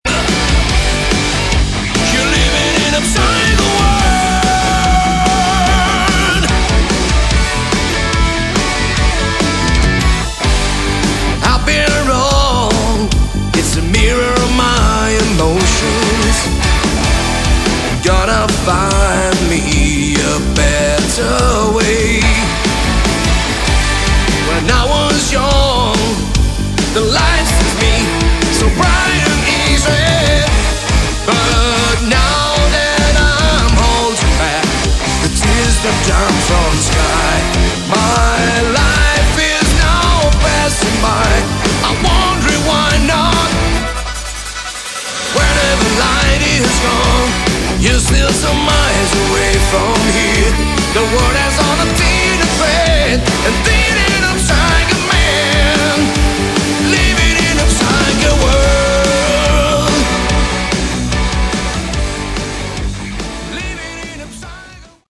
Category: Prog Rock
piano, keyboards
guitars
drums